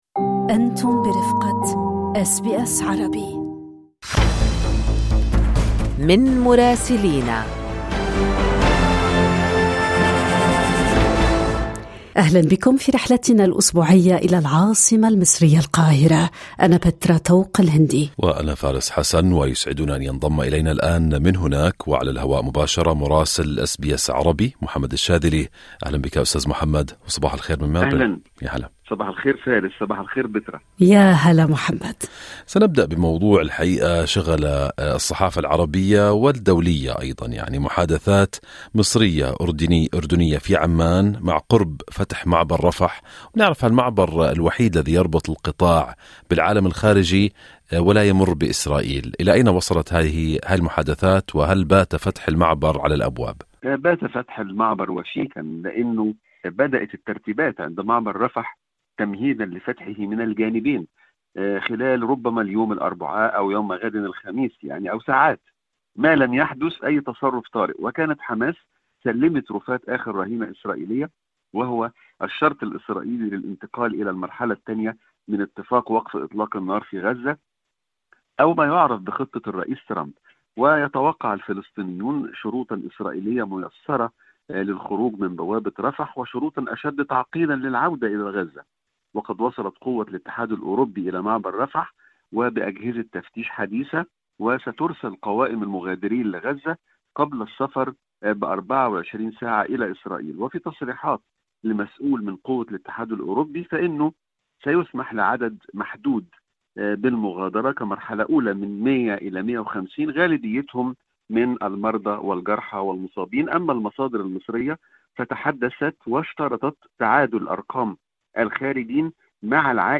لقراءة محتوى التقرير الصّوتي، اضغط على خاصيّة Transcription في الصورة أعلاه.